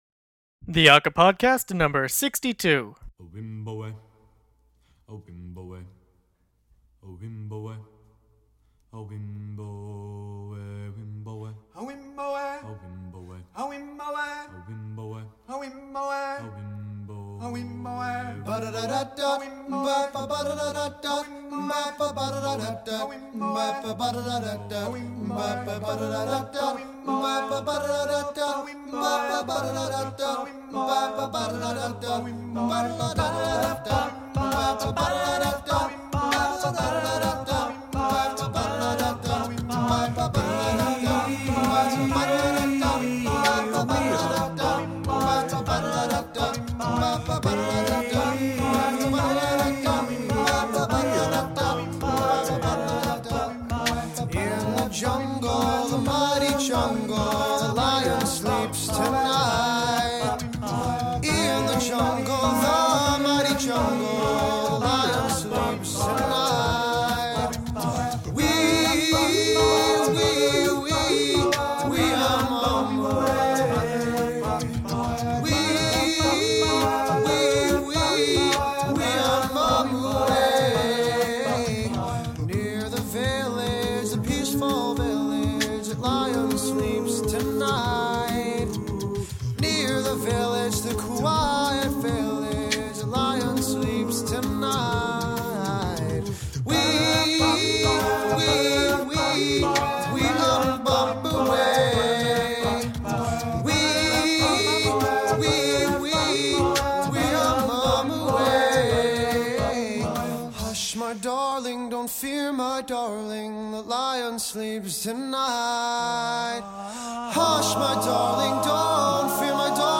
This time with a focus on High School a cappella, and the ICHSA competition.